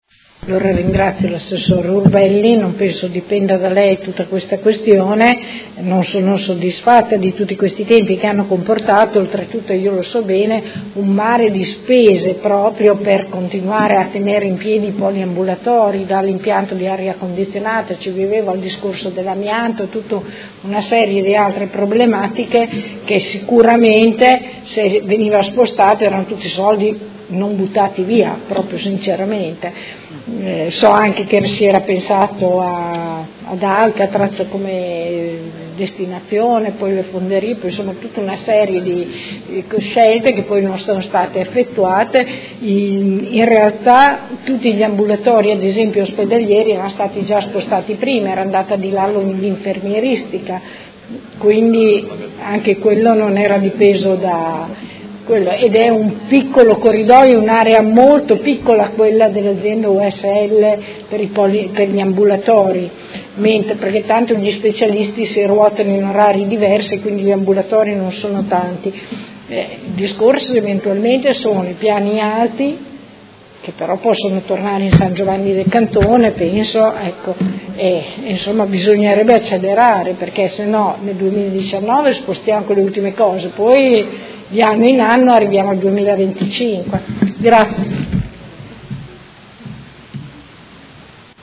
Seduta del 28/03/2019. Conclude interrogazione della Consigliera Santoro (Lega Nord) avente per oggetto: Antisismica Poliambulatorio Policlinico